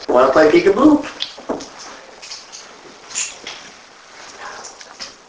Electronic Voice Phenomena (EVP)